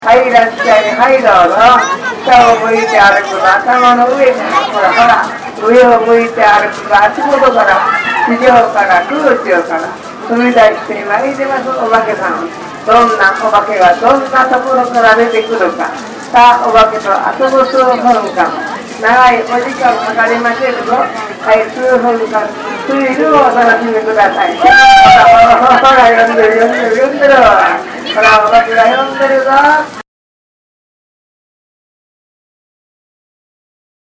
祭りの中MacBookを持って録音をしてきました
予想以上に内臓マイクの音質が良かったのですが、